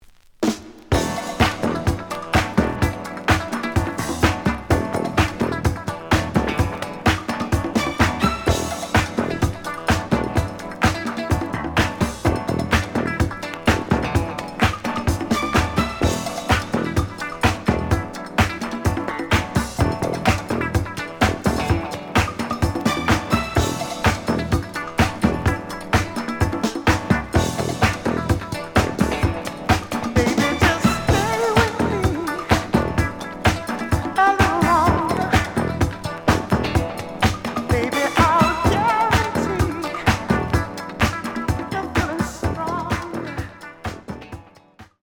The audio sample is recorded from the actual item.
●Genre: Disco
Slight damage on both side labels. Plays good.)